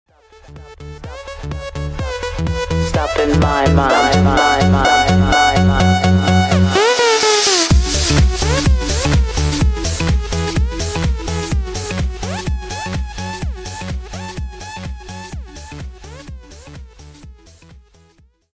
Sound Buttons: Sound Buttons View : Drop Alert
drop-alert.mp3